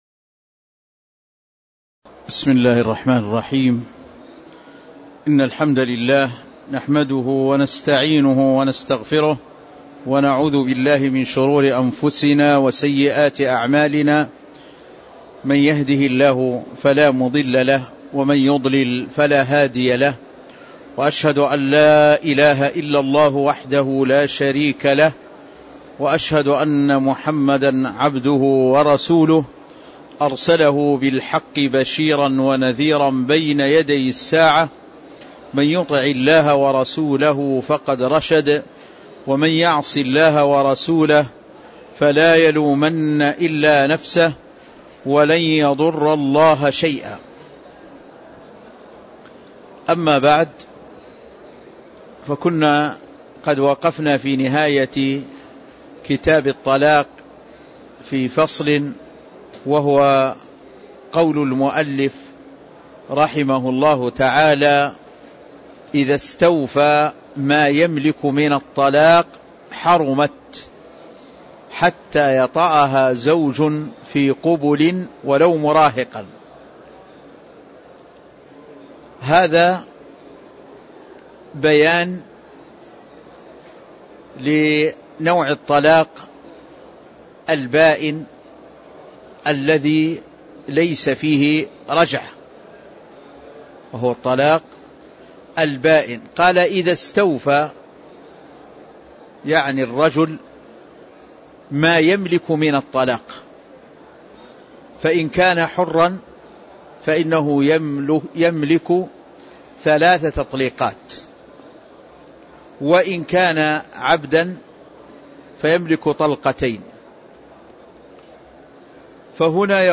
تاريخ النشر ١٧ رجب ١٤٣٧ هـ المكان: المسجد النبوي الشيخ